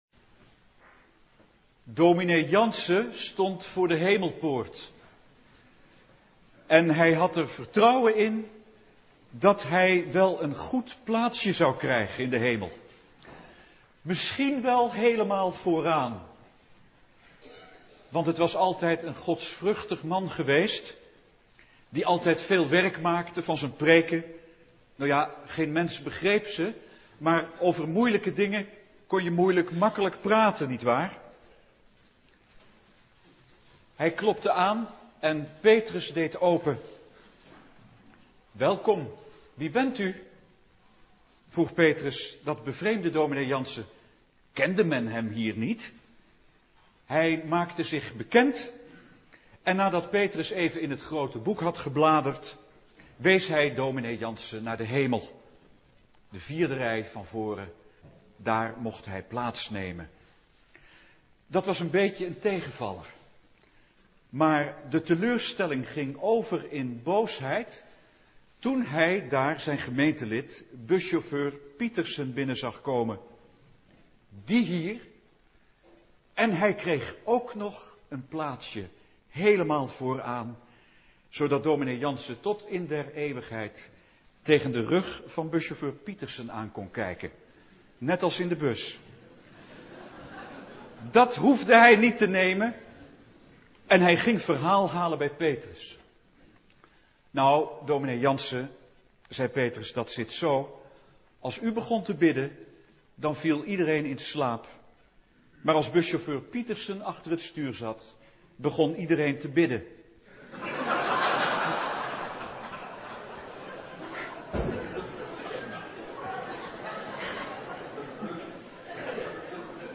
preekpinksteren2006.mp3